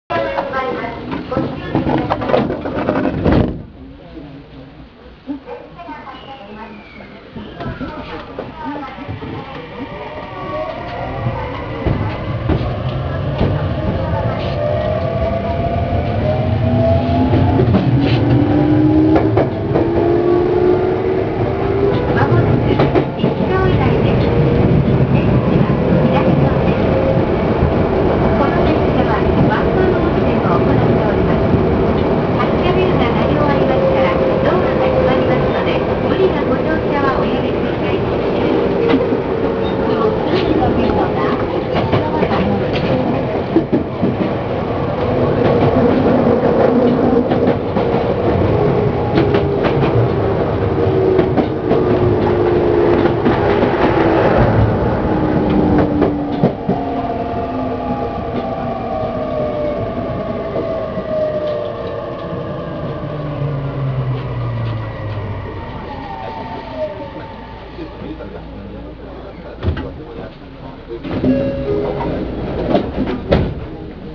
・1000系1500番台走行音
【池上線】雪が谷大塚〜石川台（1分14秒：404KB）
編成組み換えの際にVVVFもIGBTに交換されましたが、東芝製のものを採用しており、あまり聞く事が出来ないモーター音を聞く事が出来ます。ドアチャイムは特に変更されていません。